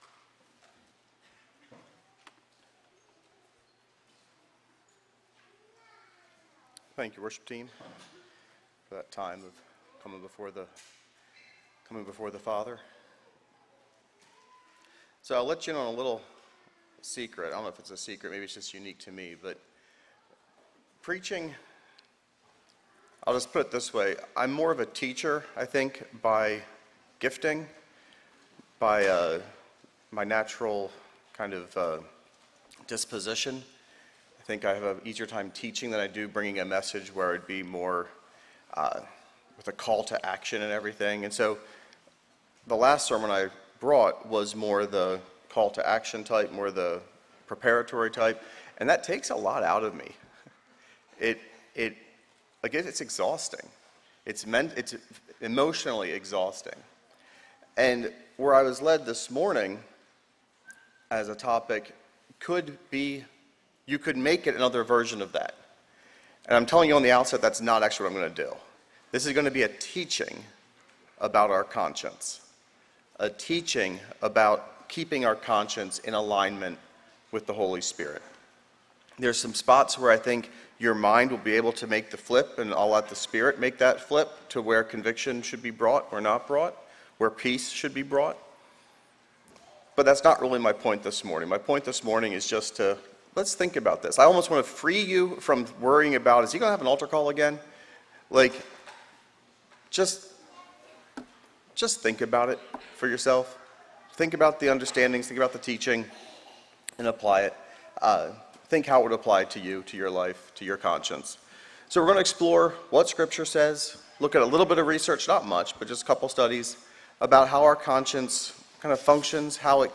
Sermon Archive | - New Covenant Mennonite Fellowship